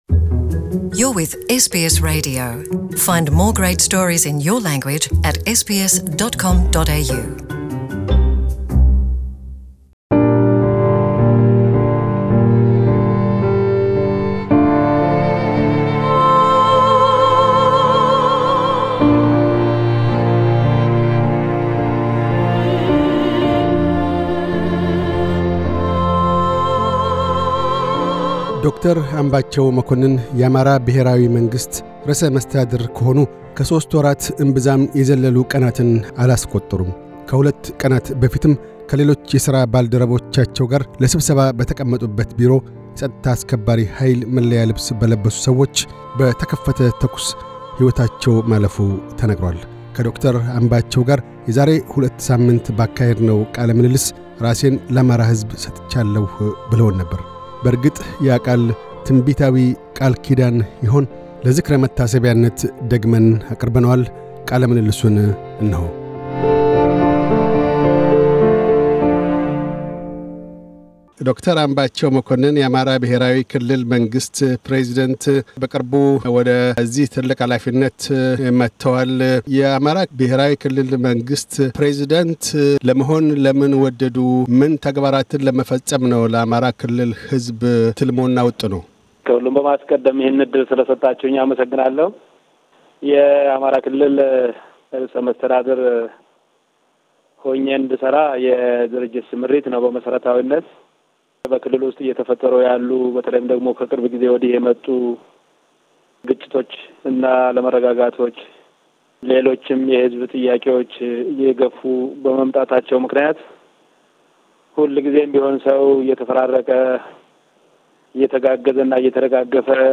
ከዶ/ር አምባቸው መኮንን፤ የአማራ ብሔራዊ ክልል መንግሥት ርዕሰ መስተዳድር ጋር የዛሬ ሁለት ሳምንት ተነጋግረን ነበር።
ቃለ ምልልሱን ለዝክረ መታሰቢያነት ደግመን አቅርበነዋል።